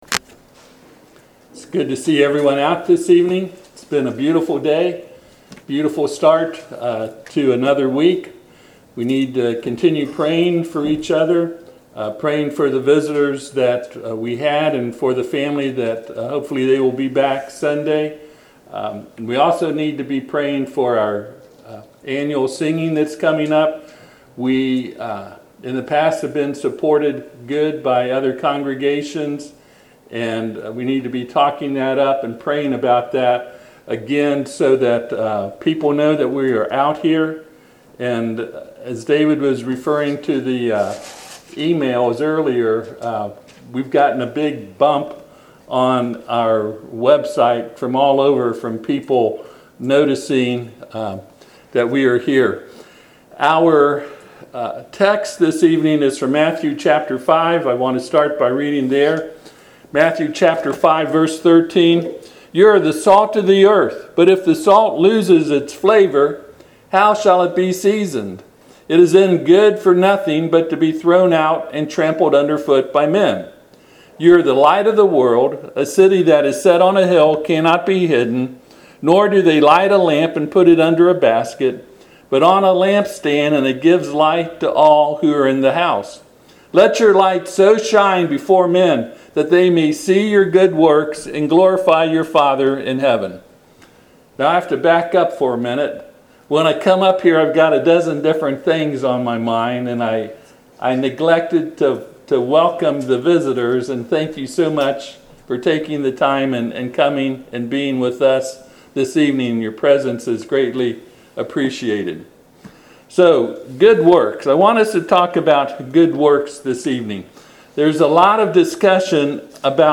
Passage: Matthew 5:13-16 Service Type: Sunday PM